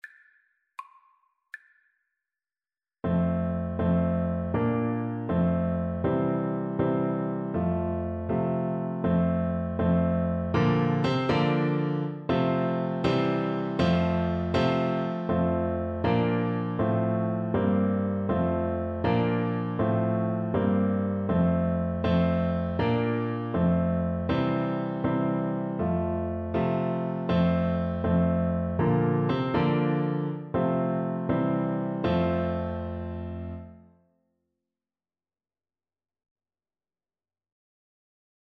6/8 (View more 6/8 Music)
Lively